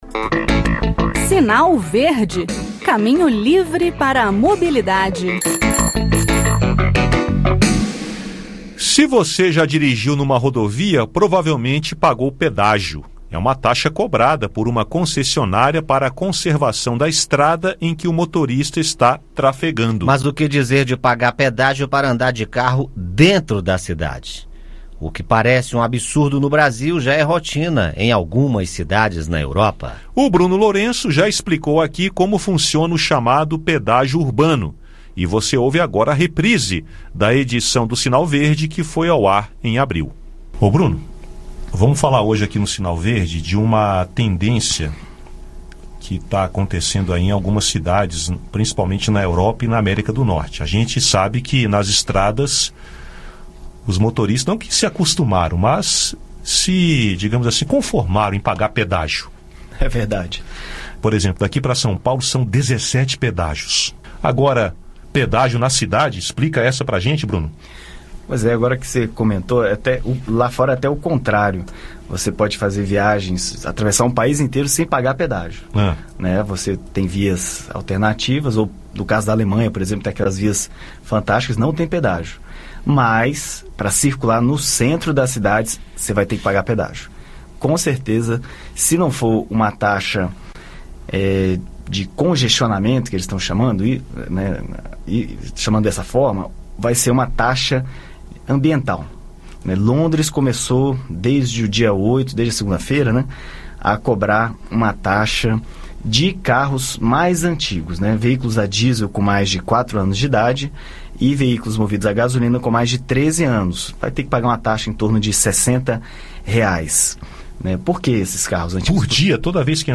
No "Sinal Verde" de hoje o tema é pedágio dentro das cidades. Ouça o áudio com o bate-papo.